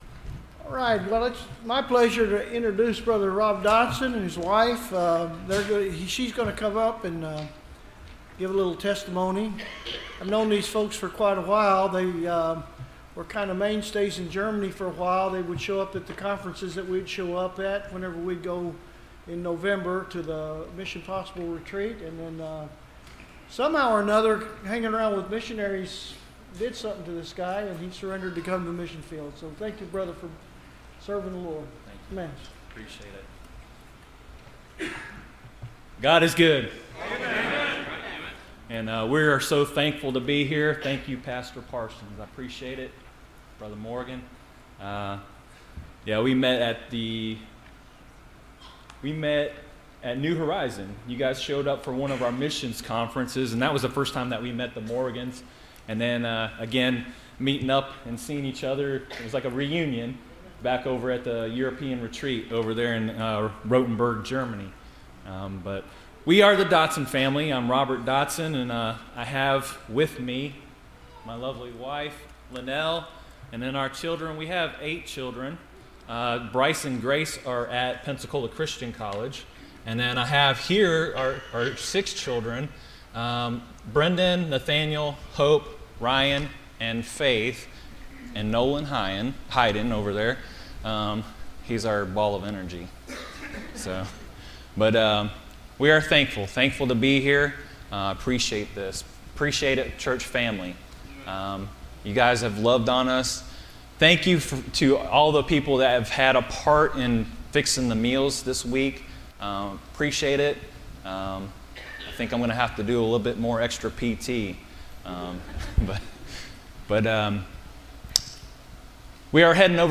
Service Type: Missions Conference